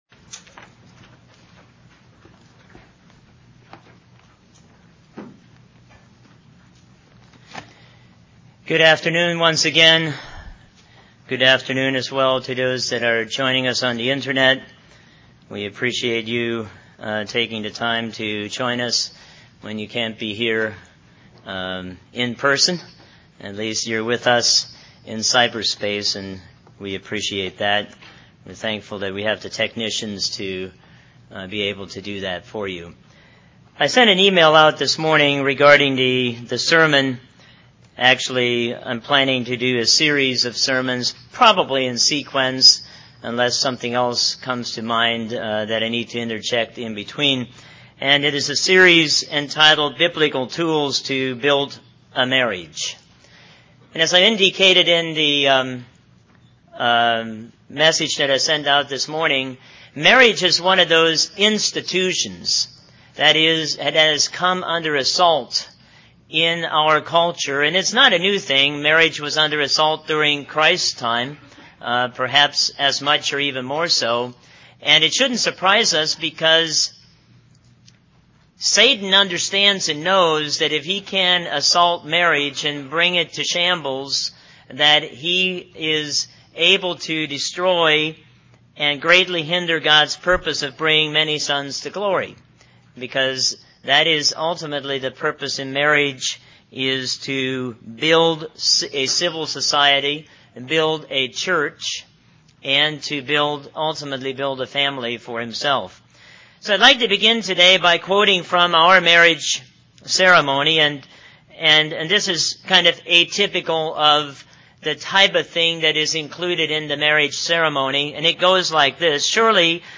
God created marriage to fulfill a need: that man should not be alone. He also provided tools in order to better guarantee a happy marriage; this sermon addresses the tool of affection.